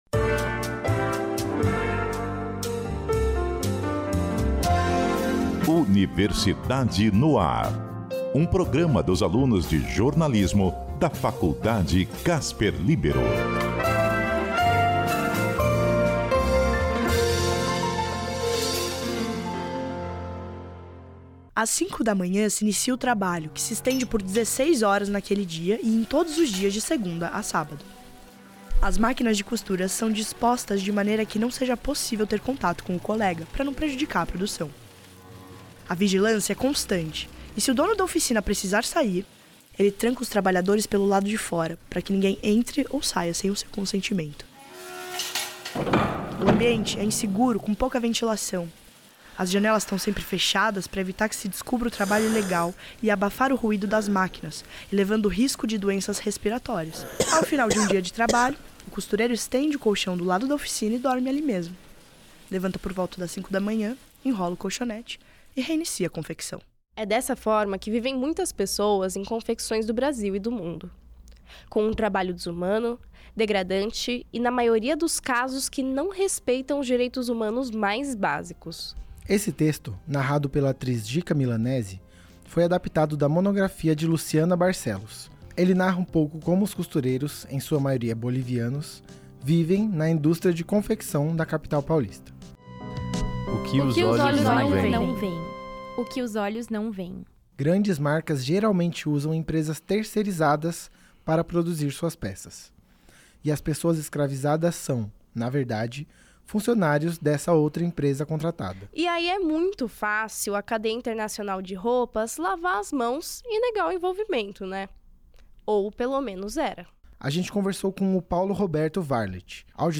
Confira a reportagem produzida por estudantes de “Jornalismo” sobre as condições de trabalho análogo à escravidão na indústria do vestuário . A matéria foi transmitida no dia 09 de agosto de 2025, pela Rádio CBN.